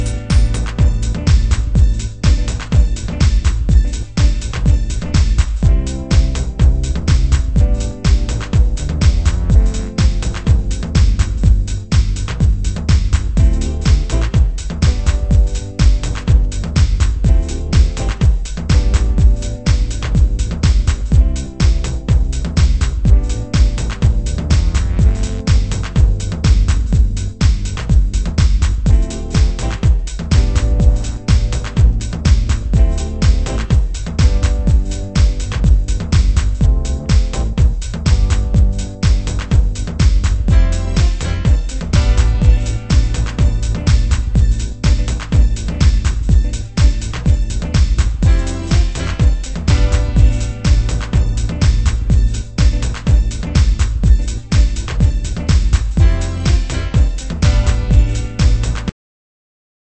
★DEEP HOUSE 歌 WHITE